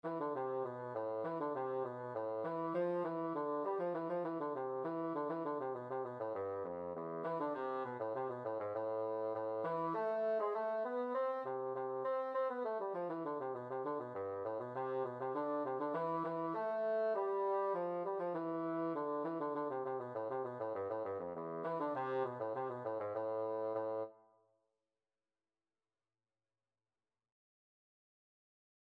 2/4 (View more 2/4 Music)
E3-C5
Bassoon  (View more Intermediate Bassoon Music)
Traditional (View more Traditional Bassoon Music)